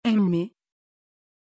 Prononciation de Emmie
Turc
pronunciation-emmie-tr.mp3